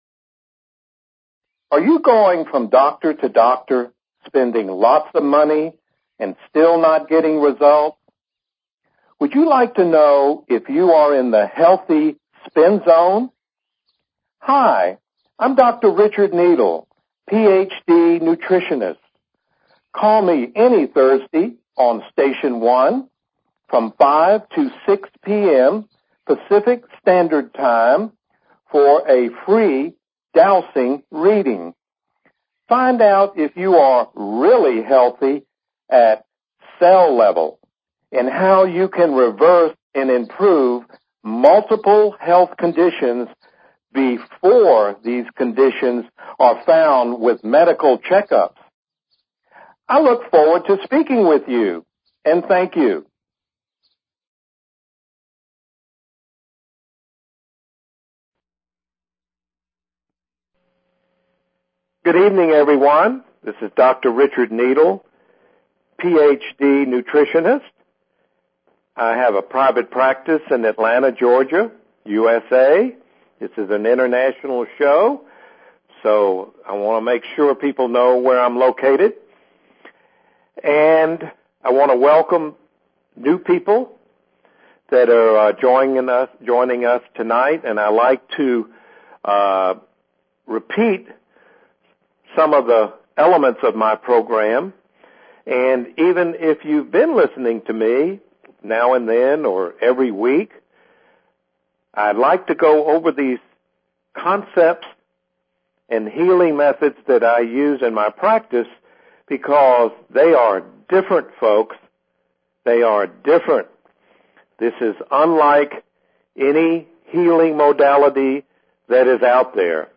Talk Show Episode, Audio Podcast, Stop_Guessing_About_Your_Health and Courtesy of BBS Radio on , show guests , about , categorized as